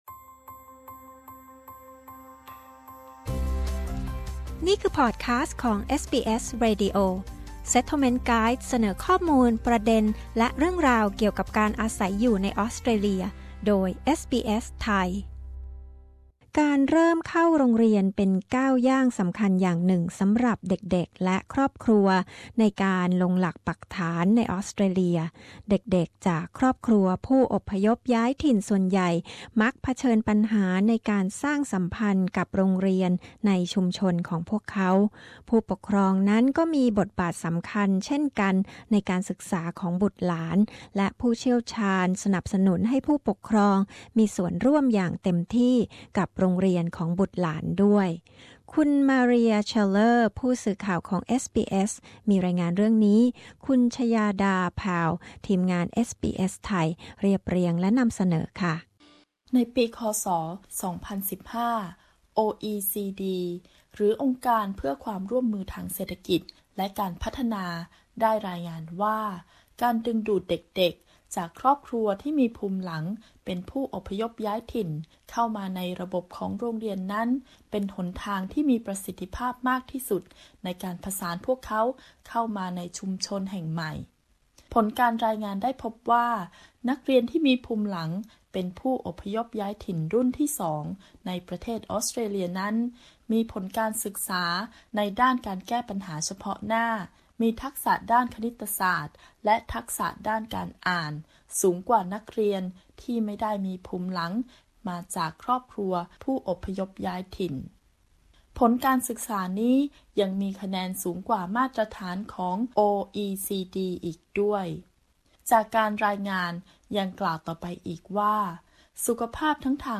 พ่อแม่ที่เพิ่งย้ายมาอยู่ในออสเตรเลีย จะช่วยลูกให้ปรับตัวเข้ากับโรงเรียนใหม่ที่นี่ได้อย่างไร และสำคัญเพียงไรที่พ่อแม่ควรเข้าไปมีส่วนร่วมอย่างเต็มที่กับโรงเรียนของลูกๆ เอสบีเอส มีรายงานพิเศษเรื่องนี้